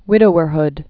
(wĭdō-ər-hd)